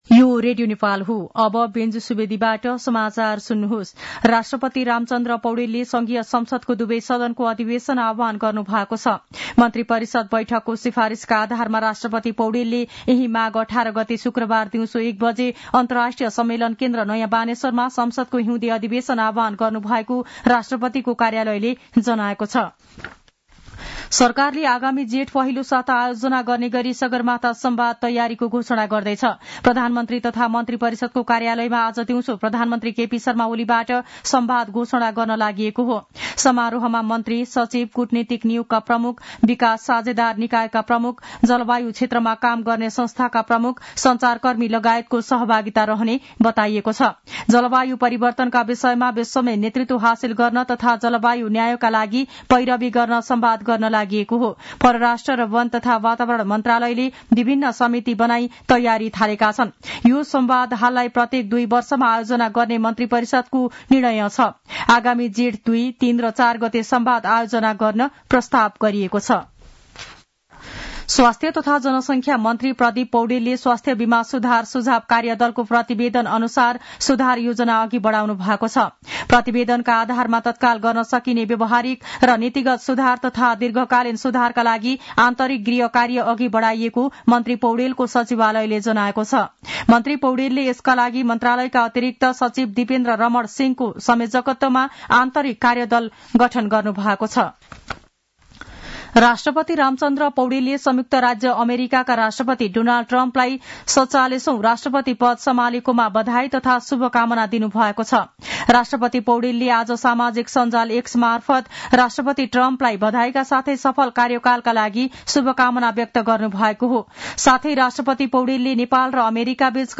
मध्यान्ह १२ बजेको नेपाली समाचार : ९ माघ , २०८१